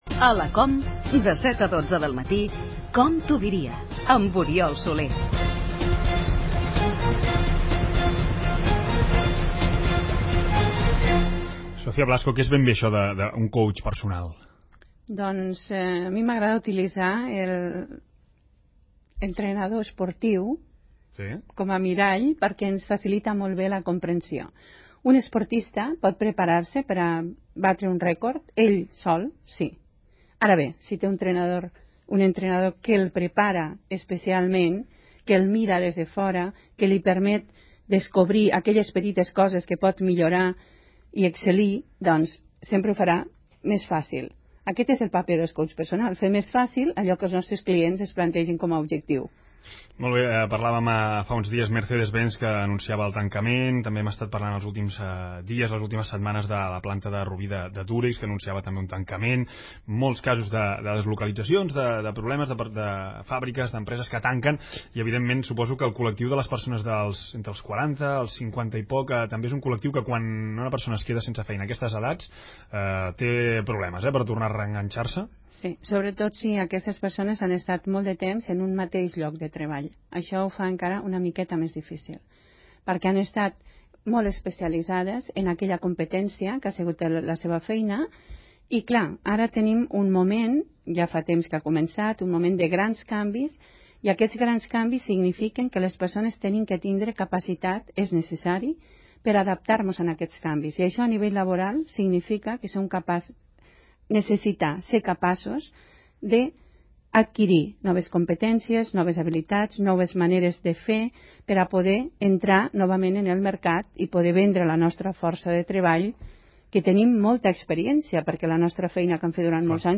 Al programa ‘COM t’ho diria’ de COMRàdio, parlant de com trobar feina més enllà dels 40 anys.